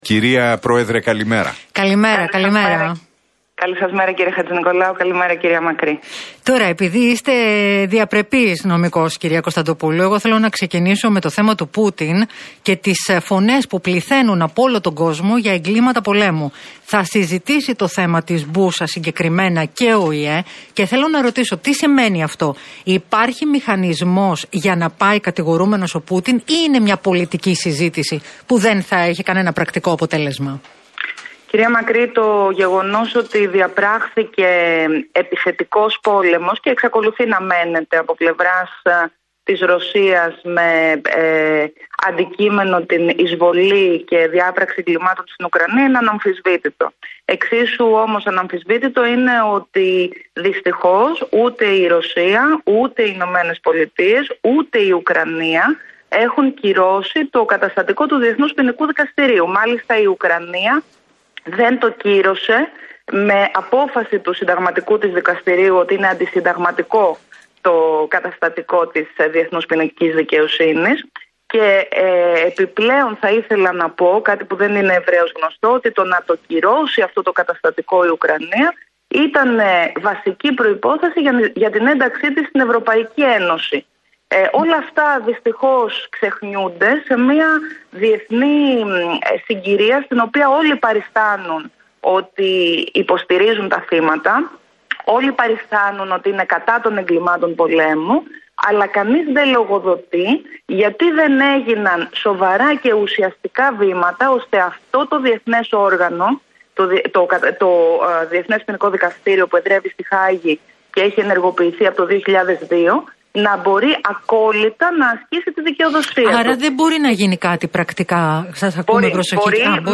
Η επικεφαλής της Πλεύσης Ελευθερίας, Ζωή Κωνσταντοπούλου σε συνέντευξη που παραχώρησε στο ραδιοφωνικό σταθμό Realfm 97,8